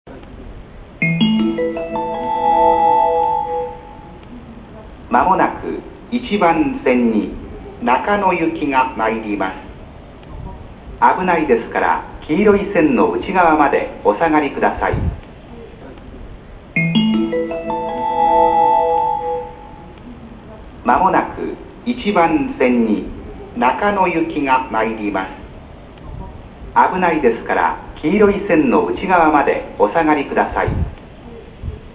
接近放送には、５打点メロディが流れる。
*実際は２回流れます。(A・B線共に平井駅で収録)*
接近放送B線三鷹方面
B線の接近放送です。
改良前は、種別と行き先の間がほとんどなく不自然な放送でした。